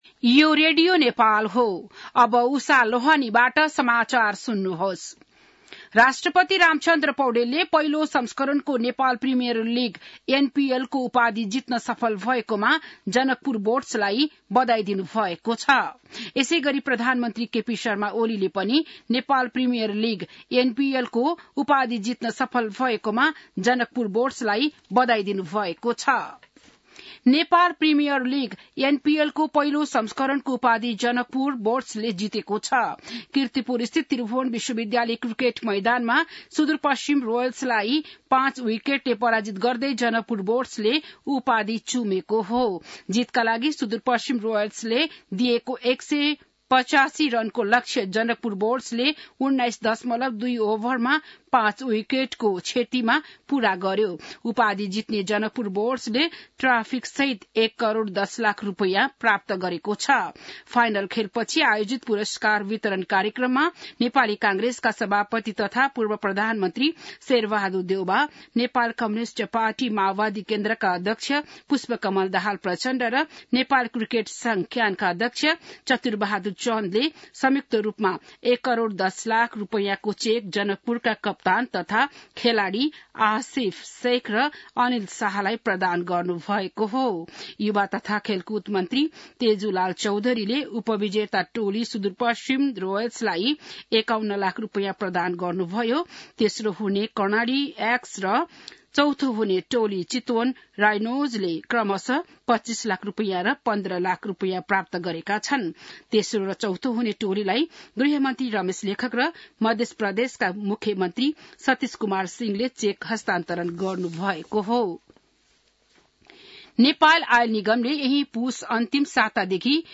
An online outlet of Nepal's national radio broadcaster
बिहान १० बजेको नेपाली समाचार : ८ पुष , २०८१